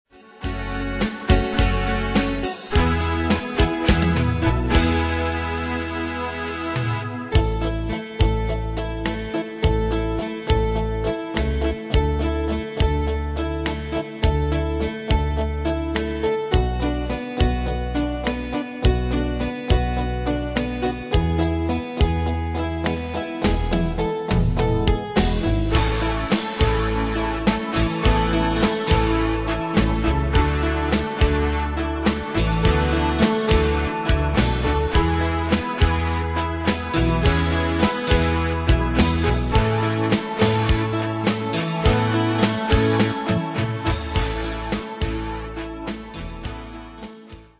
Style: PopAnglo Ane/Year: 1993 Tempo: 105 Durée/Time: 4.15
Danse/Dance: Ballade Cat Id.